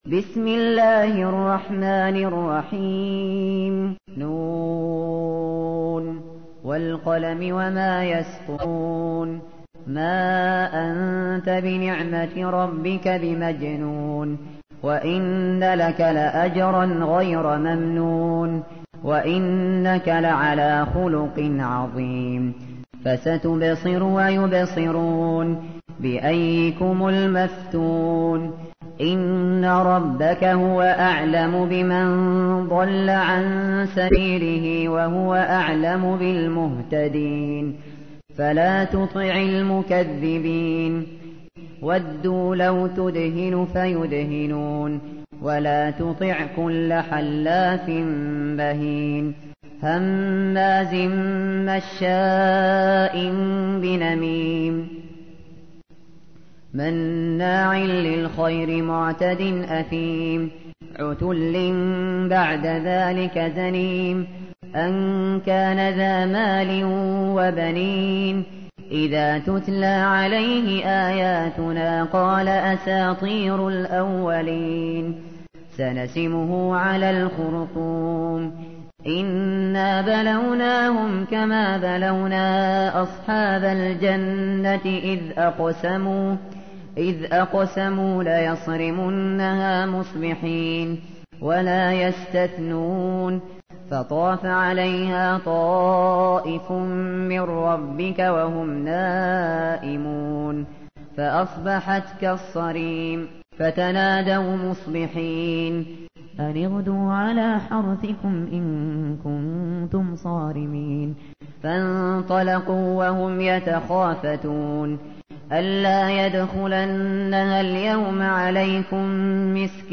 تحميل : 68. سورة القلم / القارئ الشاطري / القرآن الكريم / موقع يا حسين